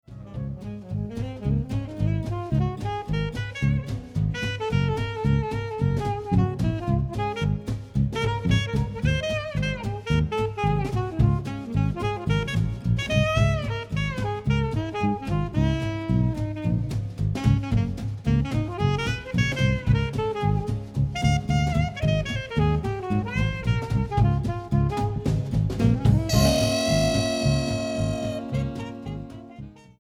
alto sax